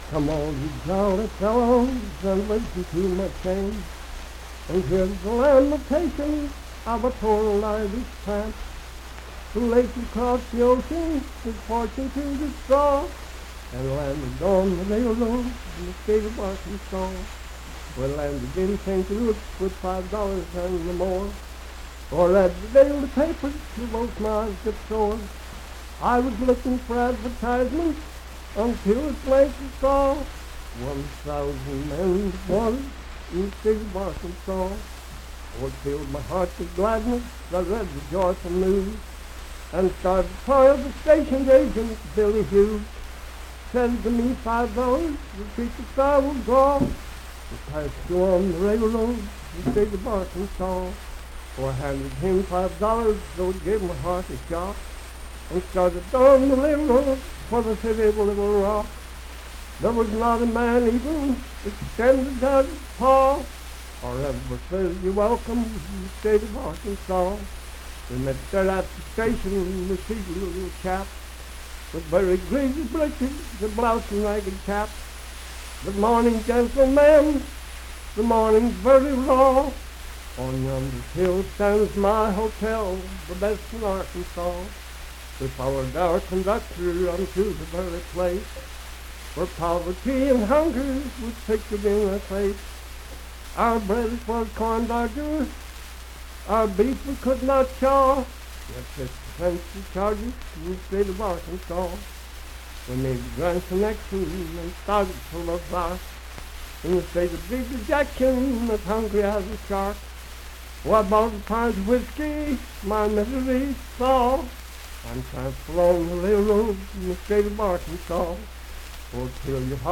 Unaccompanied vocal music
in Mount Storm, W.V.
Verse-refrain 9d(4).
Voice (sung)